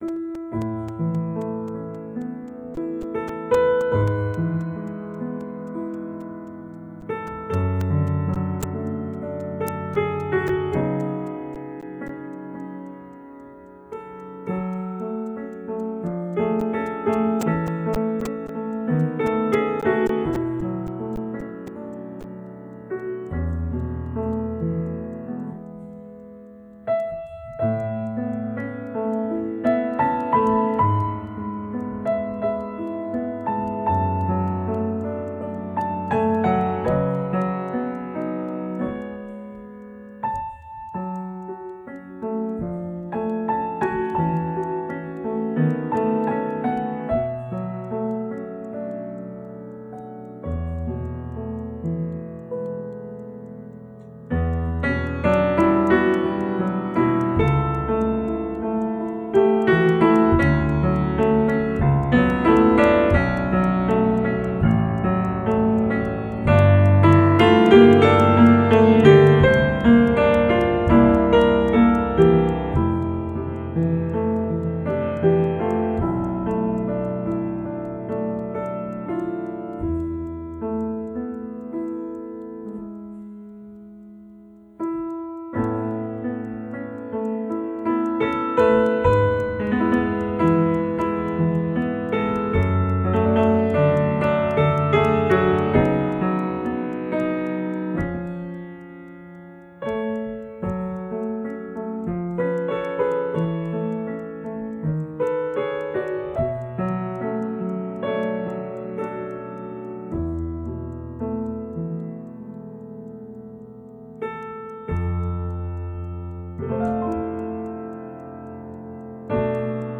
Música latina
La música de América Latina